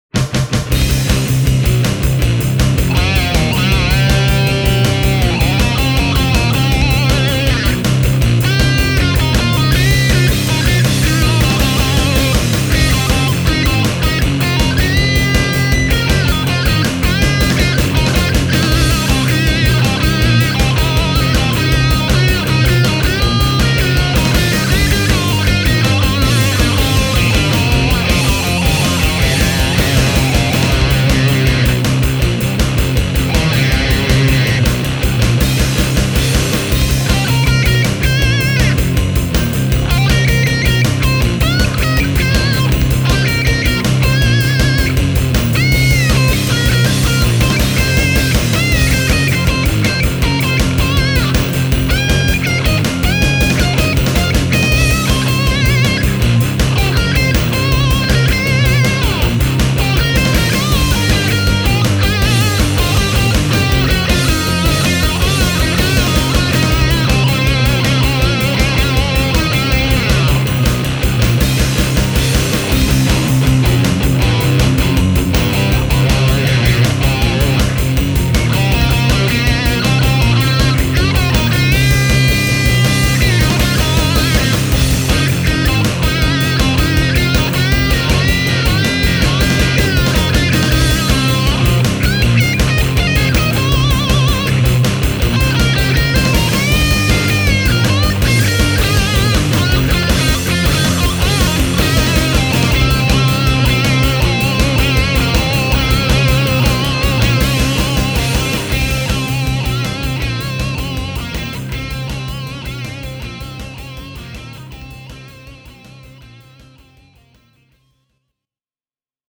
The Raato PenetRaatoR 6 Multiscale has been crafted to rock hard, and rock it does indeed: